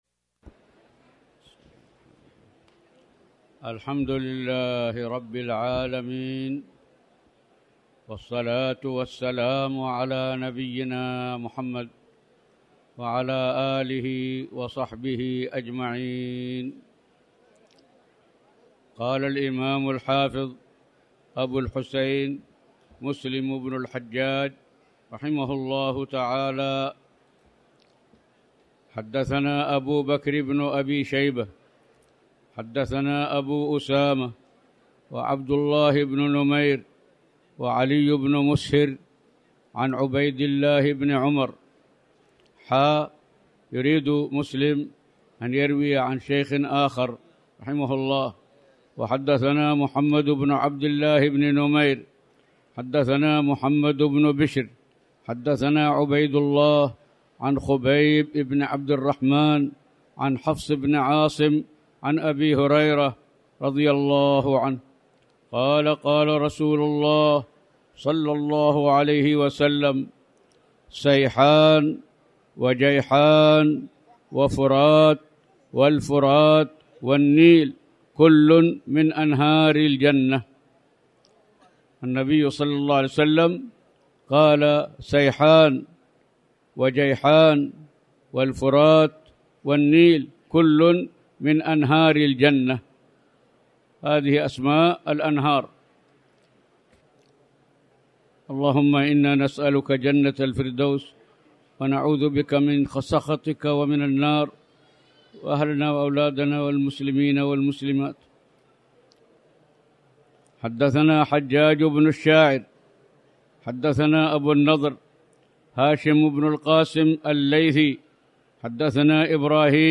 تاريخ النشر ١٤ شعبان ١٤٣٩ هـ المكان: المسجد الحرام الشيخ